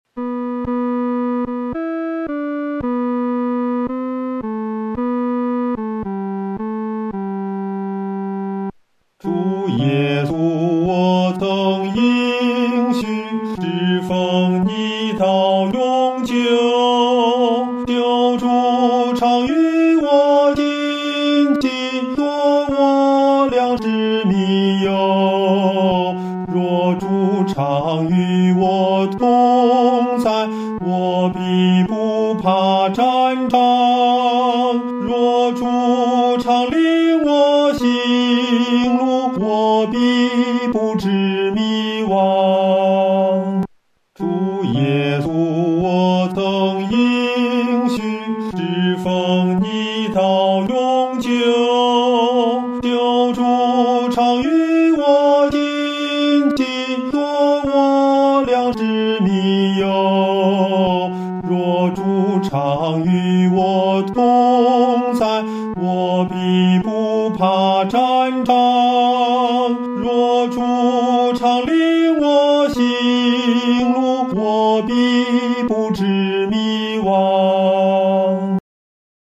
合唱
男高